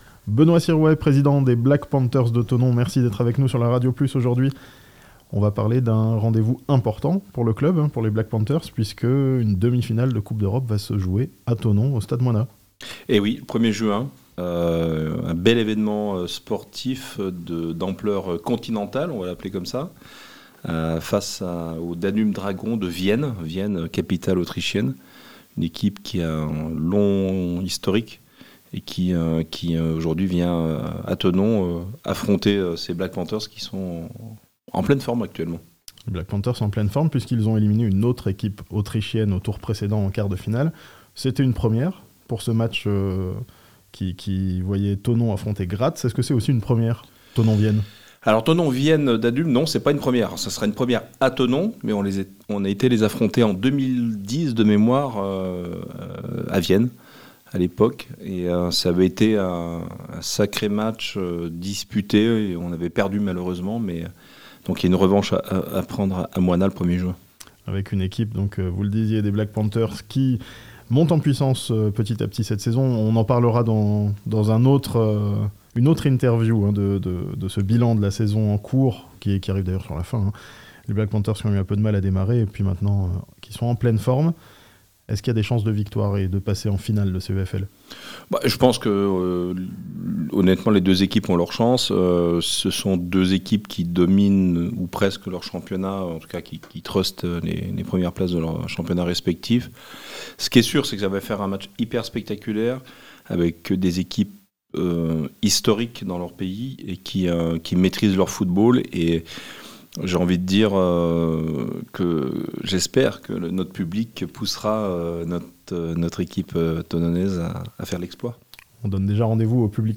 Beaucoup d'animations autour de la demi-finale européenne des Black Panthers, à Thonon ce samedi (interview)